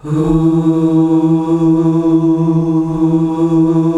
HUUUH   E.wav